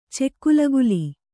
♪ cekkulaguli